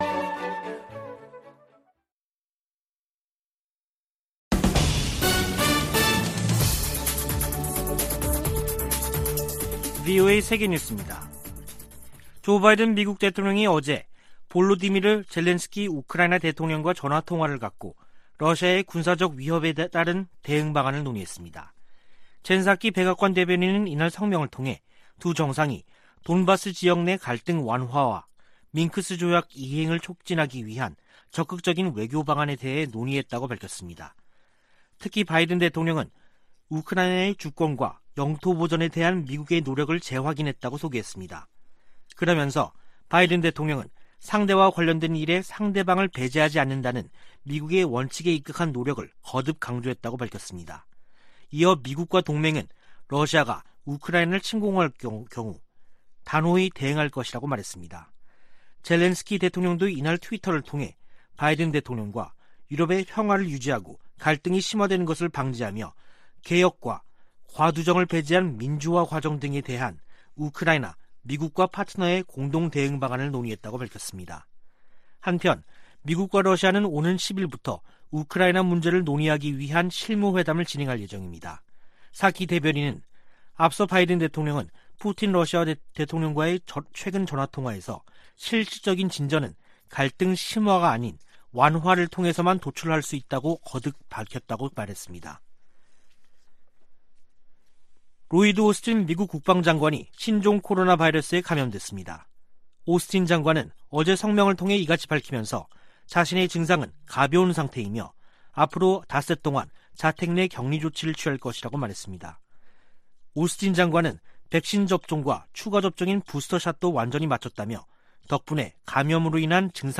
VOA 한국어 간판 뉴스 프로그램 '뉴스 투데이', 2022년 1월 3일 2부 방송입니다. 문재인 한국 대통령이 한반도 평화 제도화 노력을 멈추지 않겠다고 신년사에서 밝혔습니다. 조 바이든 미국 대통령이 역대 최대 규모의 국방예산을 담은 2022국방수권법안에 서명했습니다. 탈북민들은 새해를 맞아 미국 등 국제사회가 북한 인권 문제에 더 초점을 맞출 것을 희망했습니다.